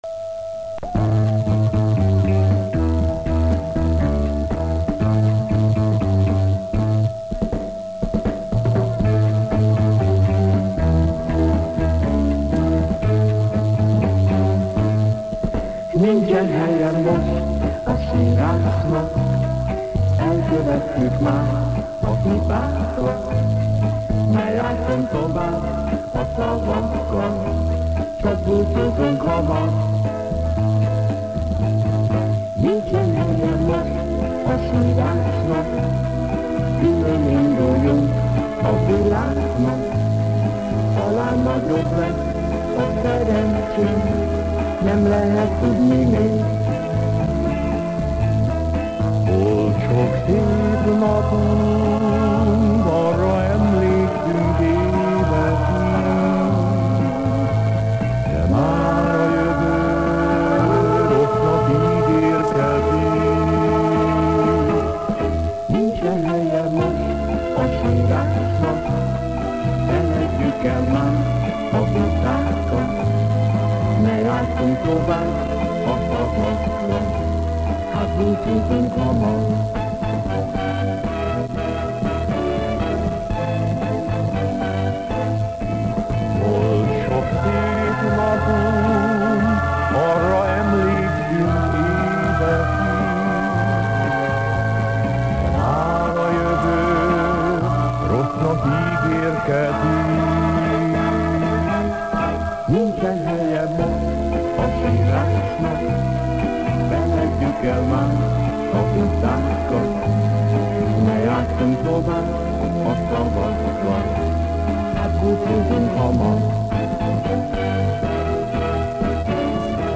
Слишком уж чисто поют и мягко =)) цитата